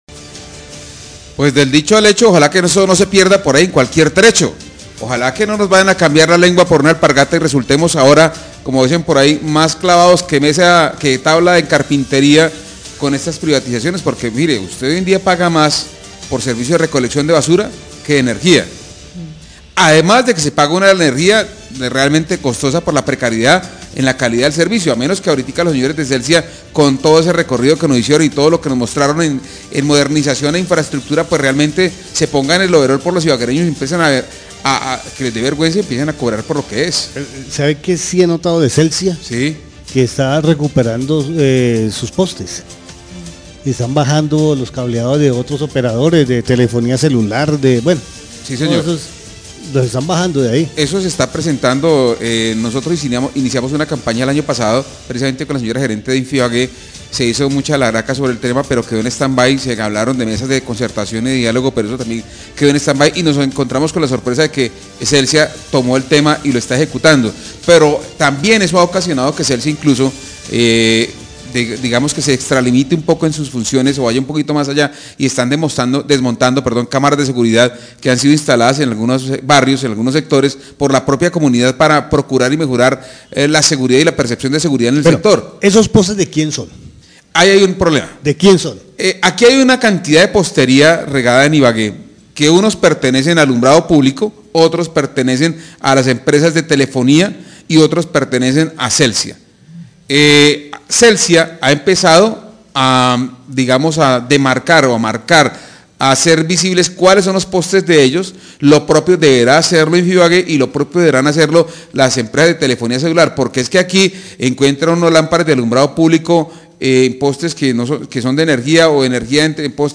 Radio
Periodistas dicen que el costo de energía es muy costoso para el tipo de servicio que aún no ha mejorado, dicen además que Celsia ha desmontado cámaras de seguridad de que han puesto las mismas comunidades para su seguridad. Dicen además el tema del cableado en Ibagué es caótico.